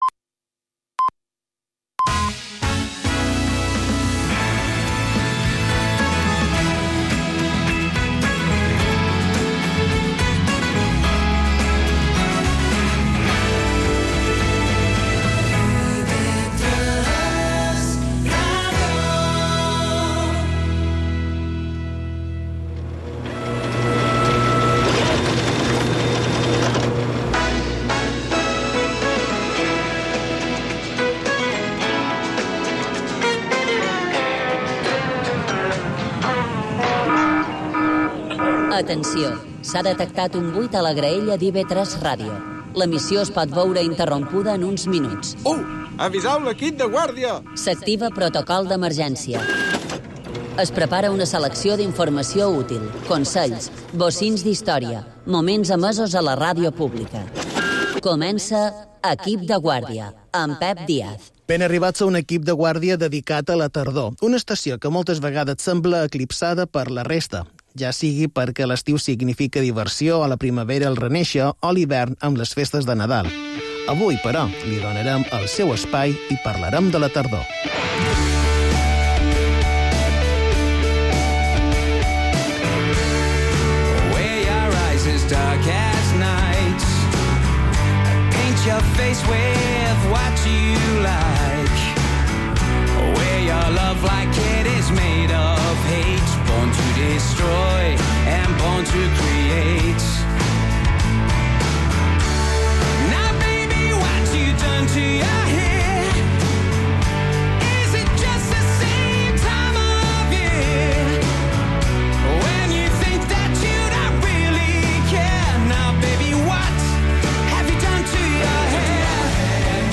Equip de Guàrdia · 2024 · Entreteniment